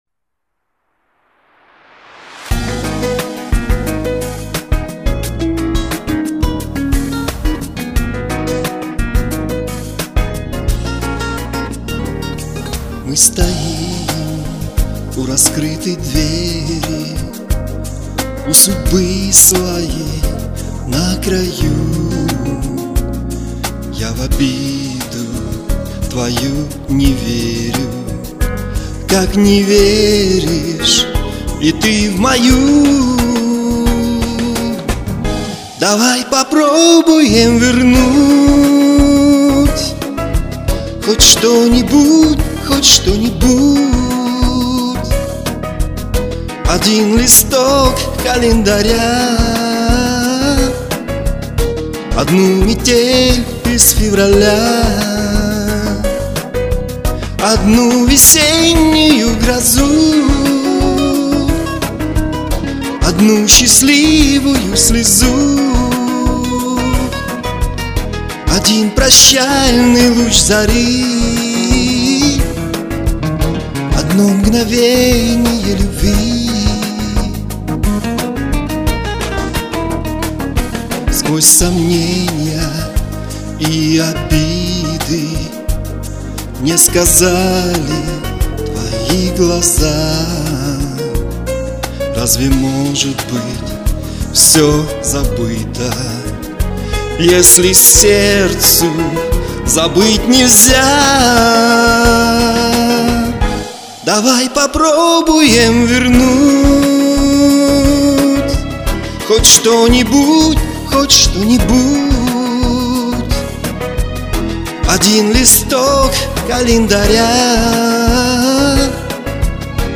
живое, эмоциональное... ощущение живого концерта